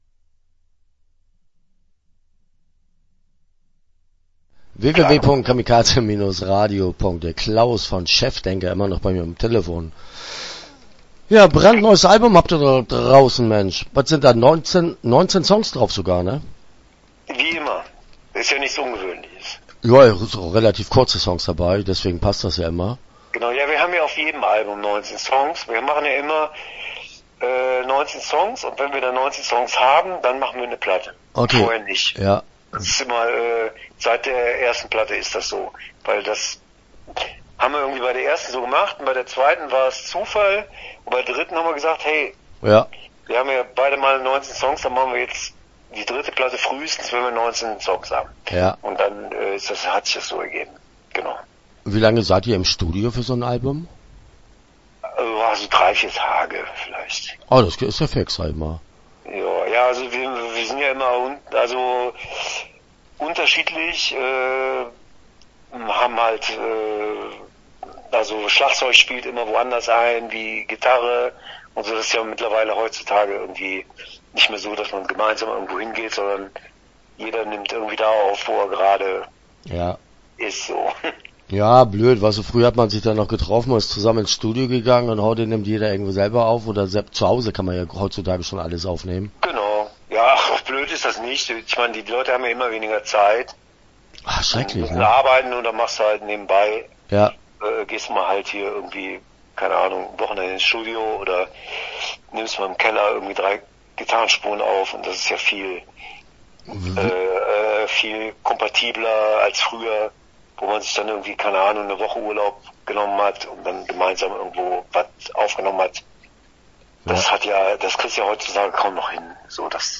Chefdenker - Interview Teil 1 (7:54)